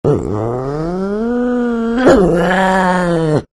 Звуки пантеры
Агрессивная пантера издает такой звук